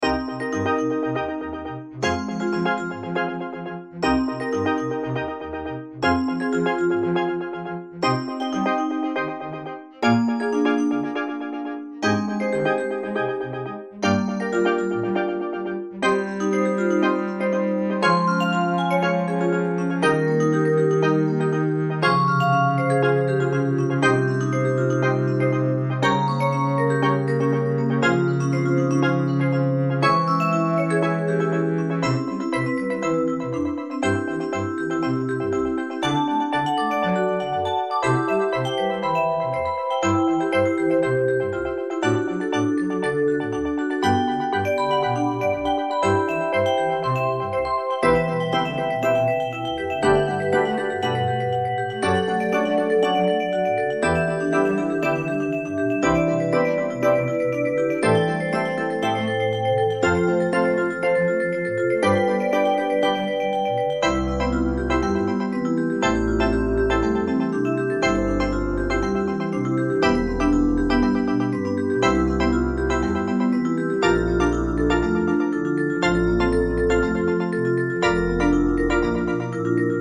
Fx3(クリスタル)、コントラバス、スティールドラム